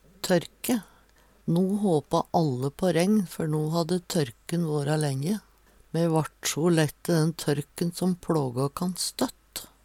tørk - Numedalsmål (en-US)